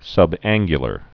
(sŭb-ănggyə-lər)